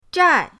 zhai4.mp3